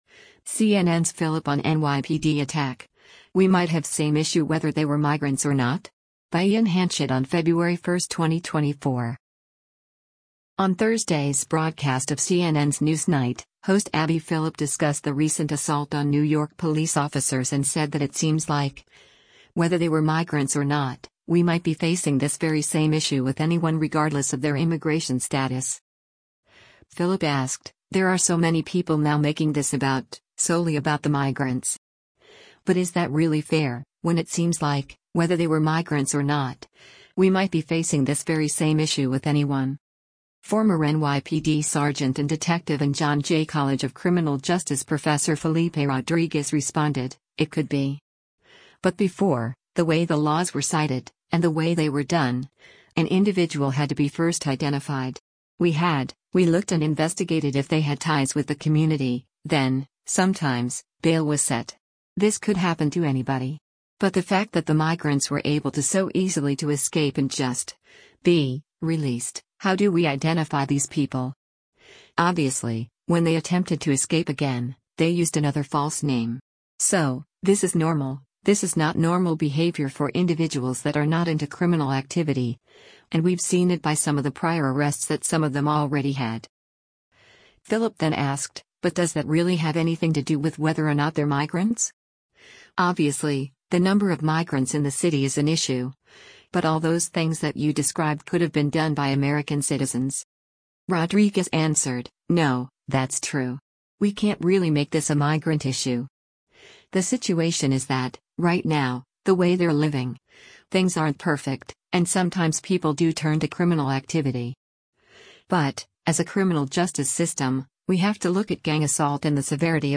On Thursday’s broadcast of CNN’s “NewsNight,” host Abby Phillip discussed the recent assault on New York police officers and said that “it seems like, whether they were migrants or not, we might be facing this very same issue with anyone” regardless of their immigration status.